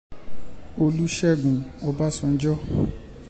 Chief Olusegun Matthew Okikiola Ogunboye Aremu Obasanjo GCFR[1][2] (// ; Yoruba: Olúṣẹ́gun Ọbásanjọ́ [olúʃɛ́ɡũ ɔbásanɟɔ]